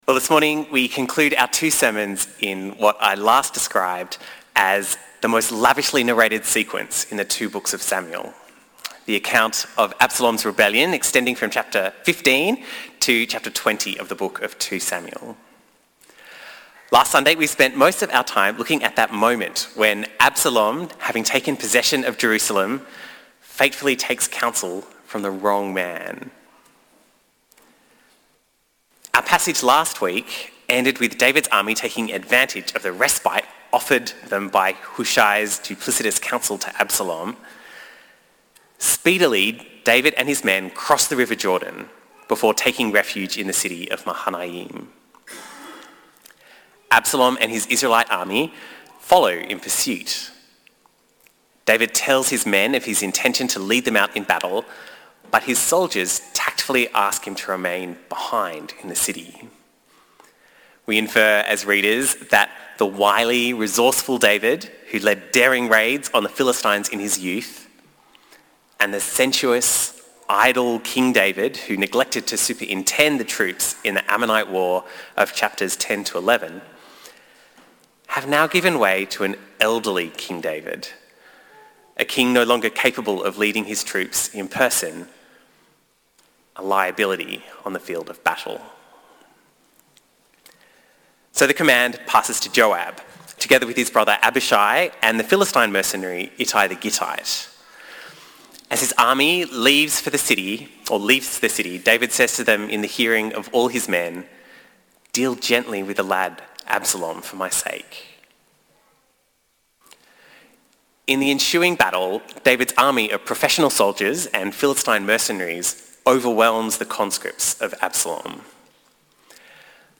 This sermon quotes the poem Mythopoeia by J.R.R. Tolkien which you can find here.